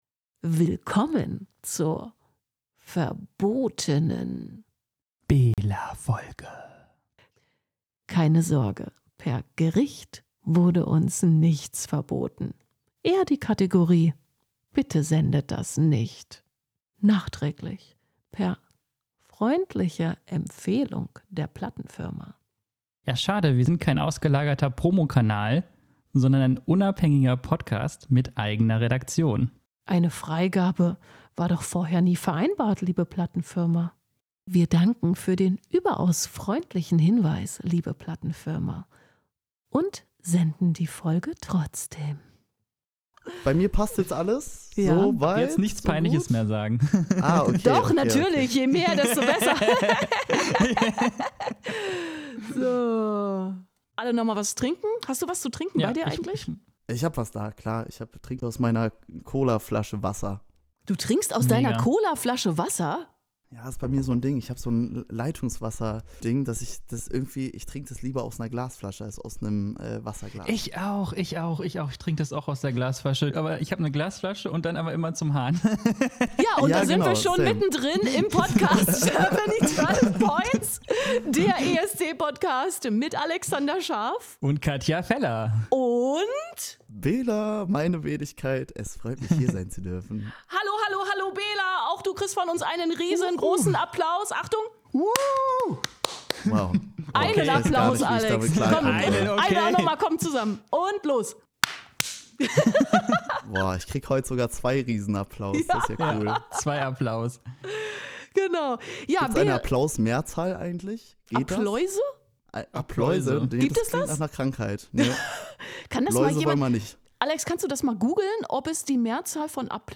Das „VERBOTENE“ Interview